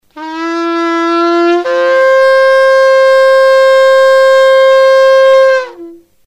A distinguishing feature of the celebration is the last, climactic blast, the
This is not the usual series of short bursts, signaling alarm or bad news. Rather, it is a long blast, signaling victory or good news.
Tekiah_Degolia.mp3